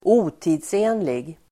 Uttal: [²'o:ti:dse:nlig]